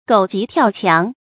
注音：ㄍㄡˇ ㄐㄧˊ ㄊㄧㄠˋ ㄑㄧㄤˊ
狗急跳墻的讀法